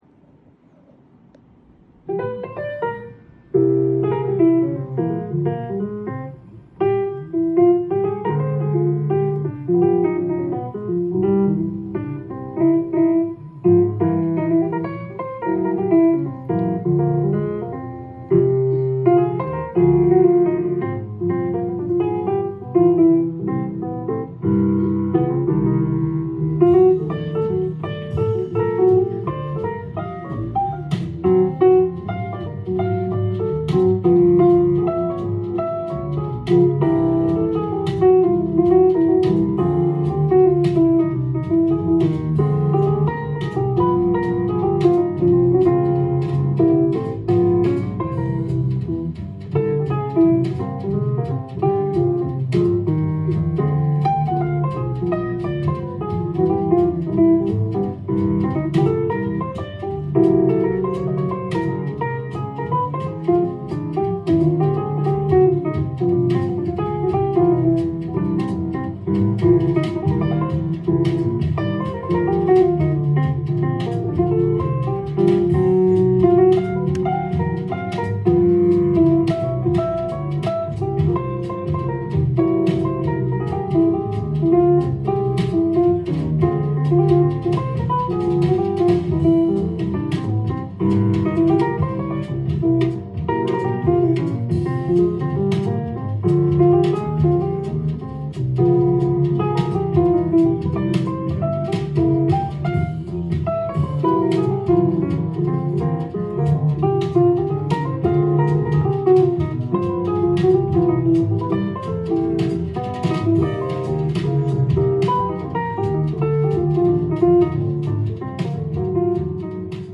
ジャンル：JAZZ-ALL
店頭で録音した音源の為、多少の外部音や音質の悪さはございますが、サンプルとしてご視聴ください。